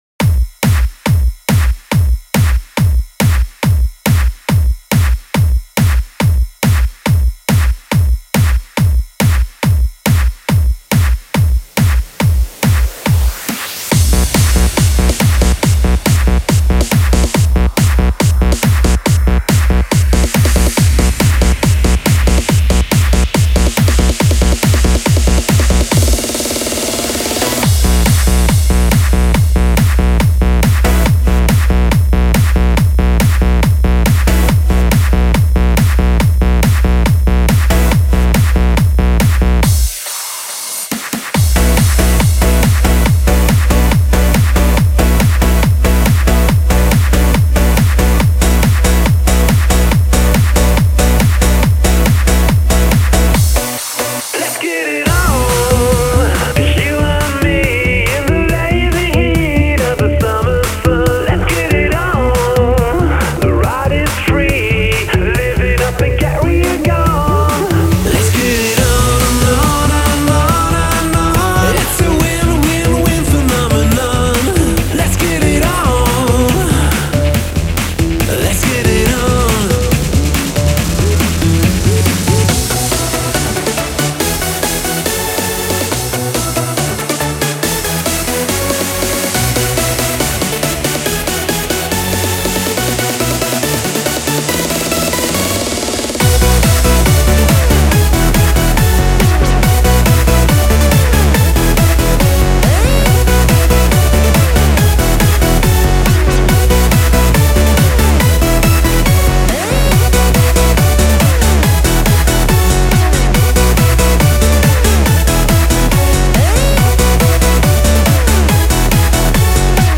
• Категория:Бодрая музыка